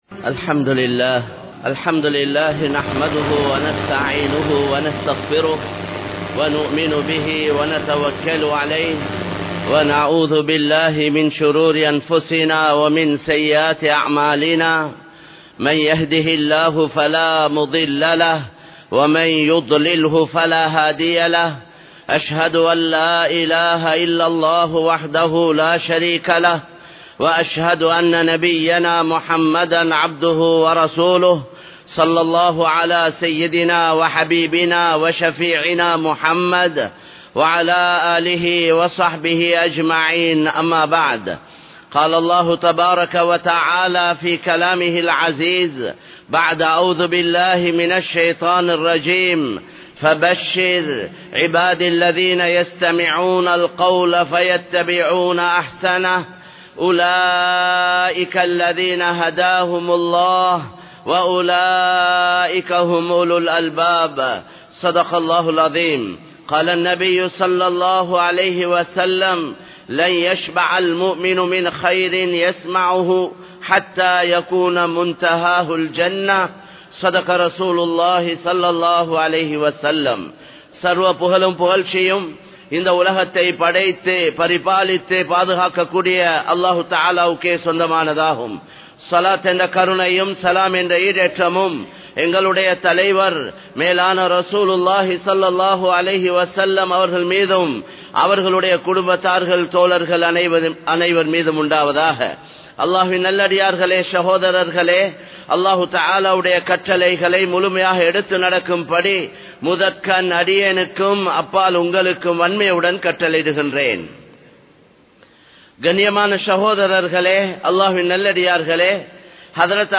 at Kollupitty Jumuah Masjid, Colombo 03